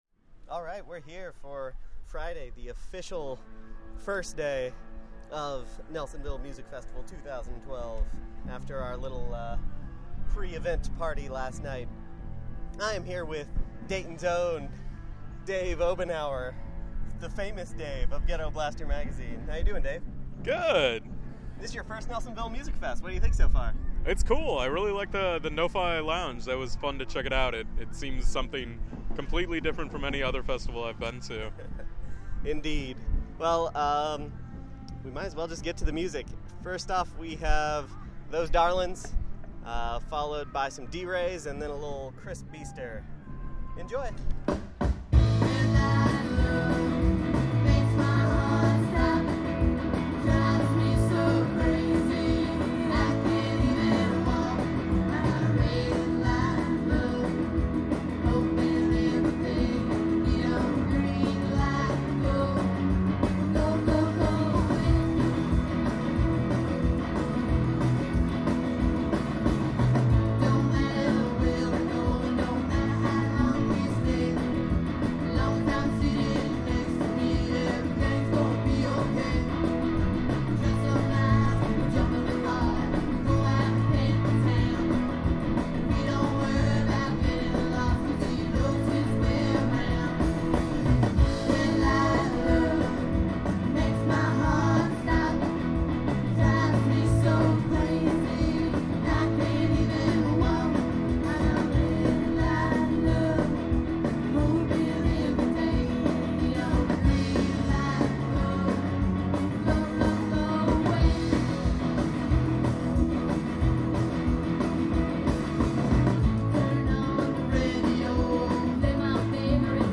groovy sounds